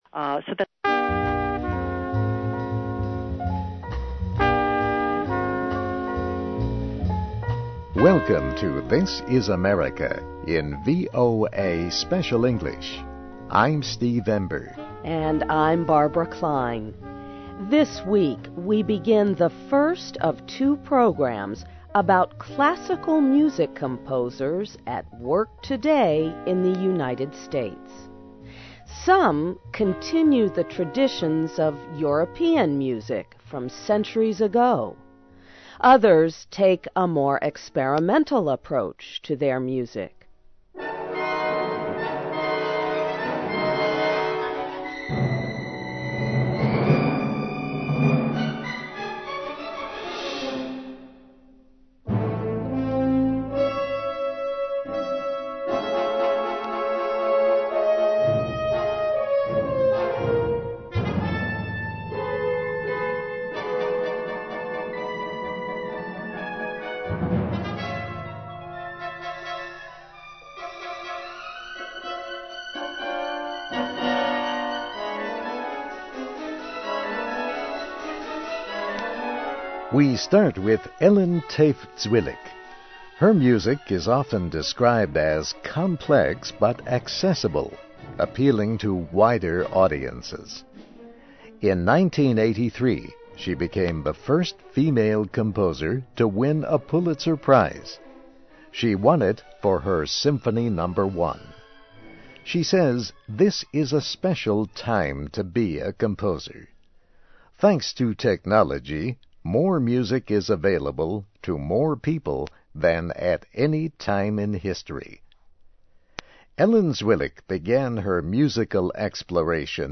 Welcome to THIS IS AMERICA in VOA Special English.